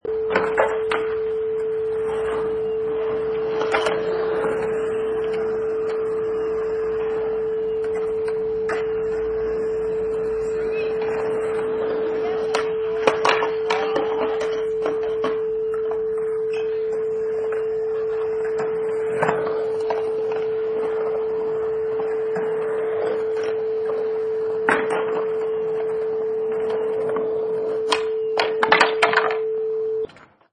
Sound waves: Skate Park 3
Sounds of a skate park
Product Info: 48k 24bit Stereo
Category: Sports / Skateboarding
Try preview above (pink tone added for copyright).
Skate_Park_3.mp3